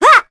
Sonia-vox-Jump.wav